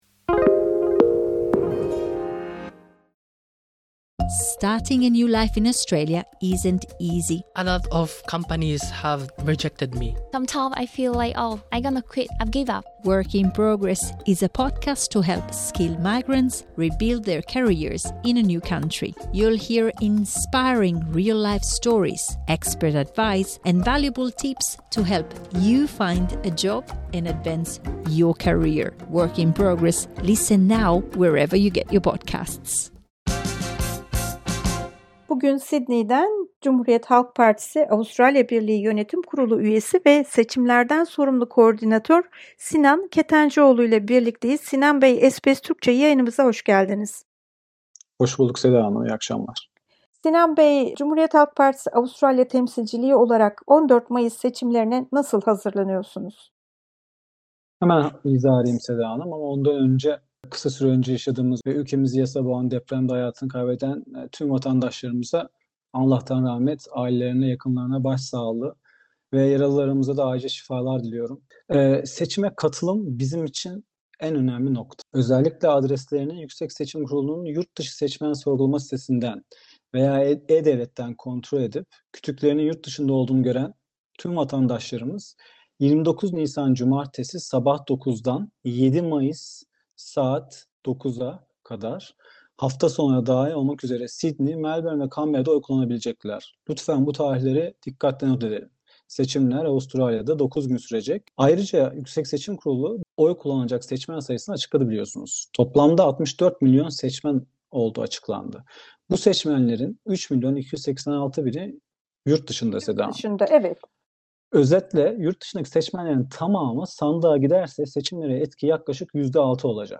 Türkiye'deki üç büyük partinin Avustralya'daki temsilcileriyle yaptığımız röportaj serimizin ikinci bölümünde